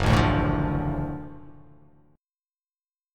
GbmM7#5 chord